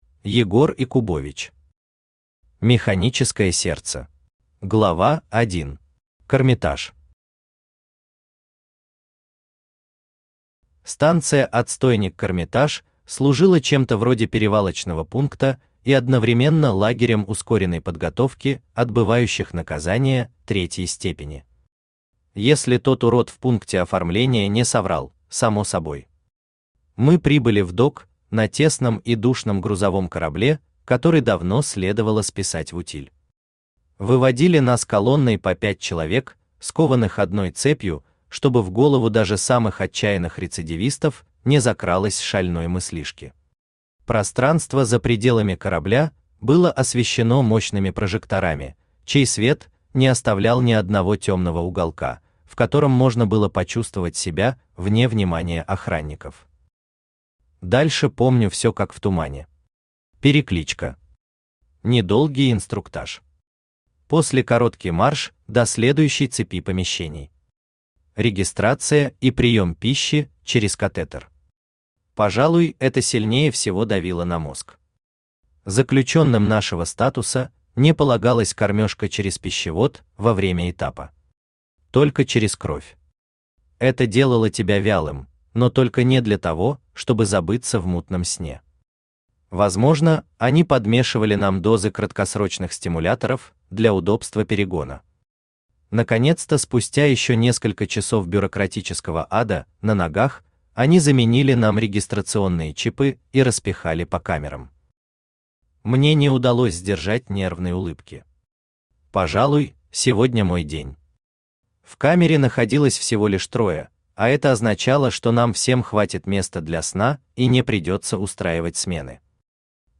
Aудиокнига Механическое сердце Автор Егор Якубович Читает аудиокнигу Авточтец ЛитРес.